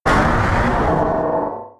Cri de Méga-Dracaufeu X K.O. dans Pokémon X et Y.